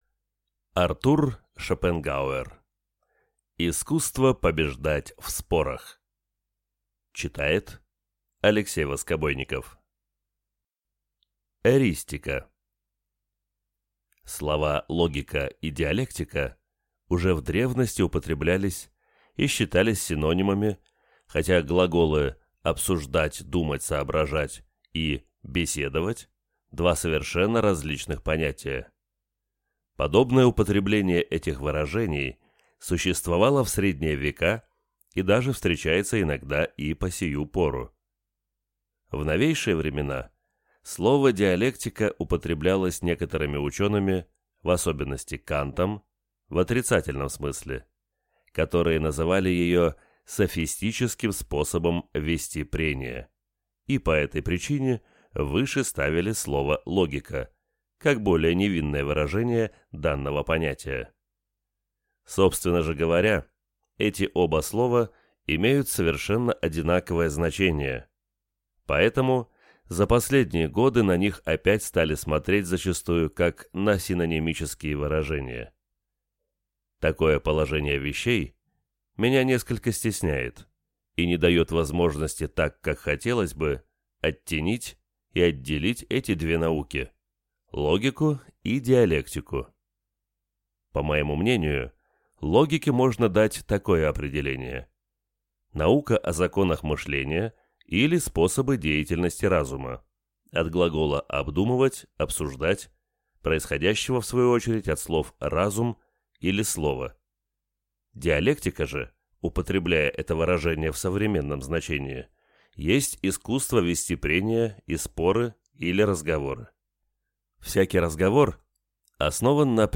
Аудиокнига Искусство побеждать в спорах | Библиотека аудиокниг